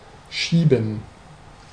Ääntäminen
IPA : /tʃɛk/ US : IPA : [tʃɛk]